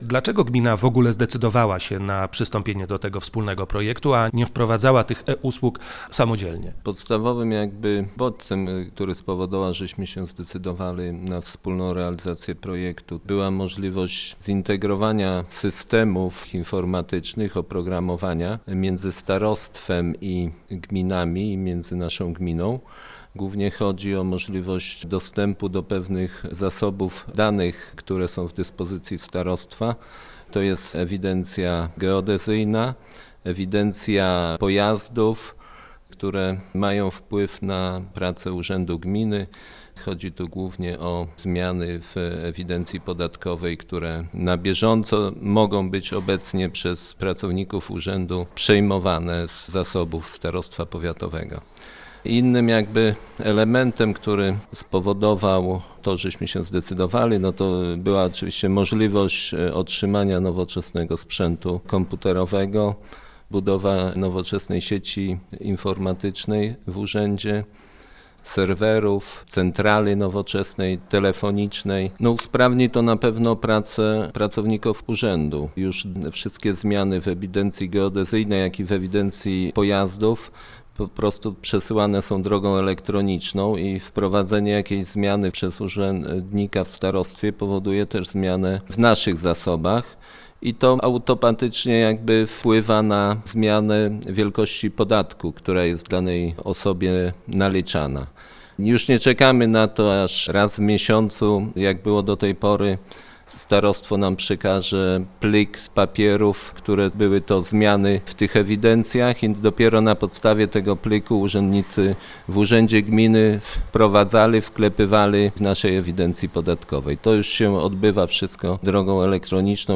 rozmawia z wójtem Jackiem Anasiewiczem: